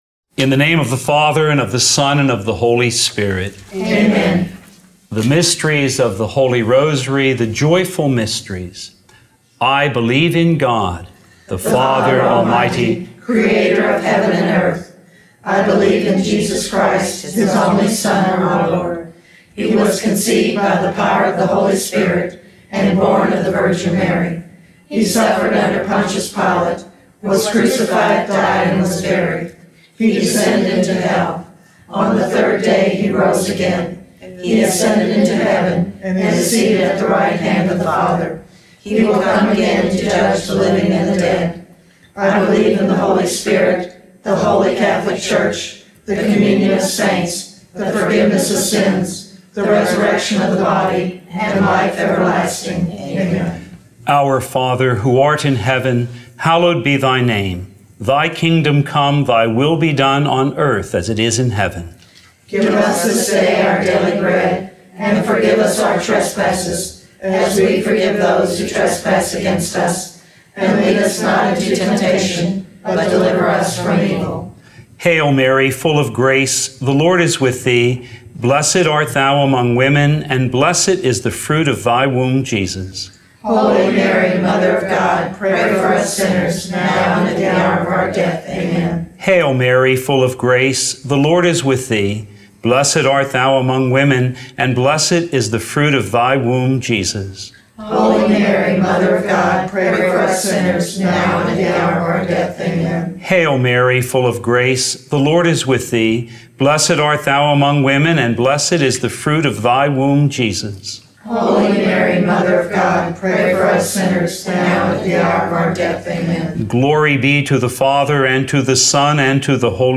Archbishop Kurtz Prays the Rosary